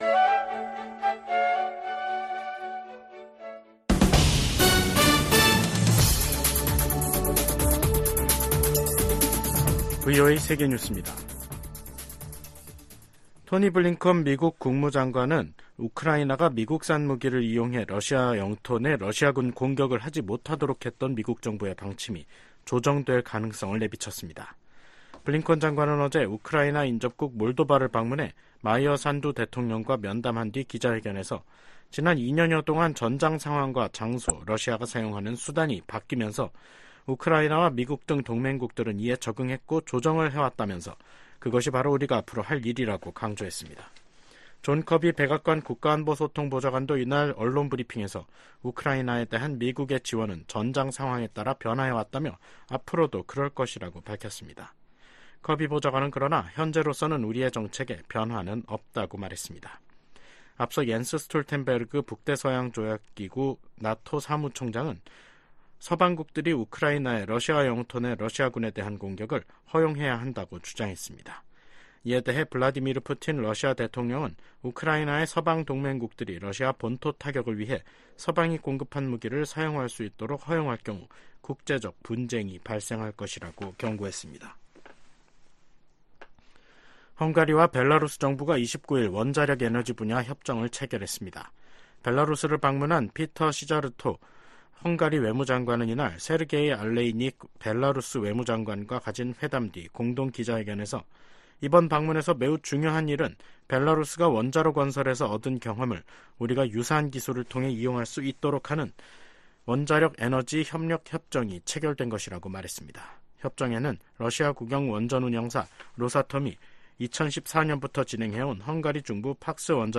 VOA 한국어 간판 뉴스 프로그램 '뉴스 투데이', 2024년 5월 30일 3부 방송입니다. 북한이 30일, 동해상으로 단거리 탄도미사일 10여발을 발사했습니다.